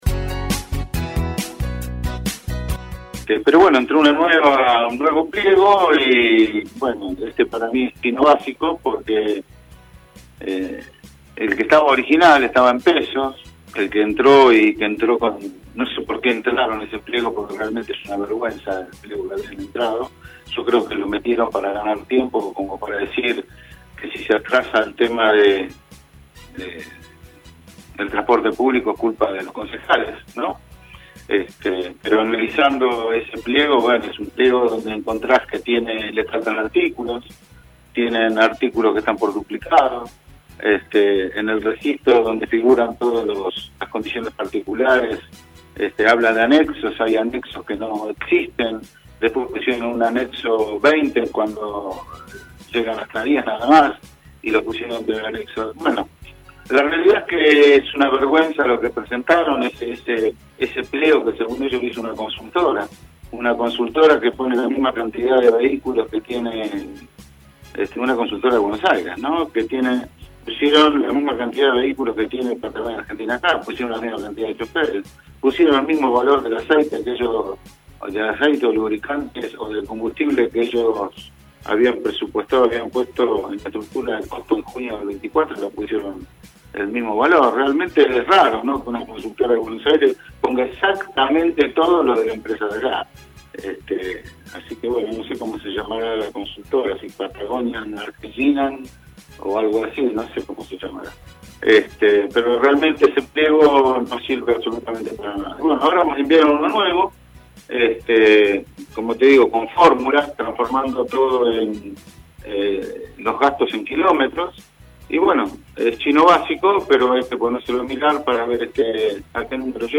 El concejal Omar Lattanzio dialogó con LA MAÑANA DE HOY respecto de lo que vió hasta el momento.